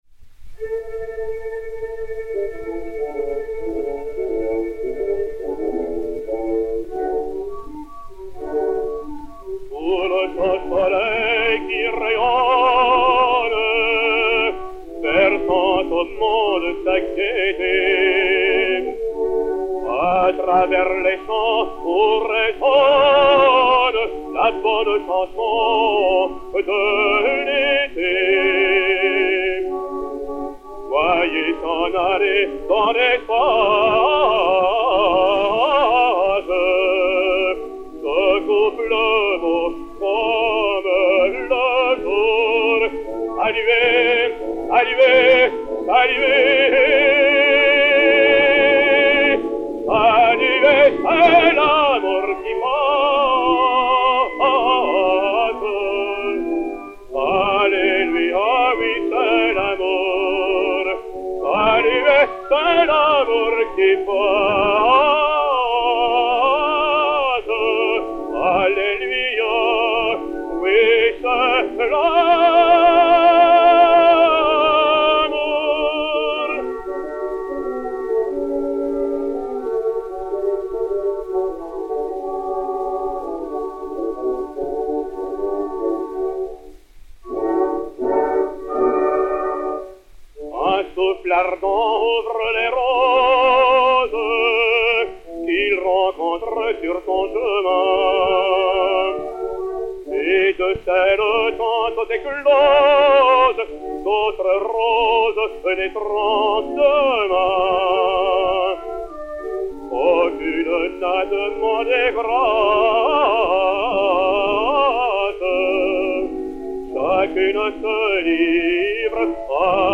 Mélodie
Orchestre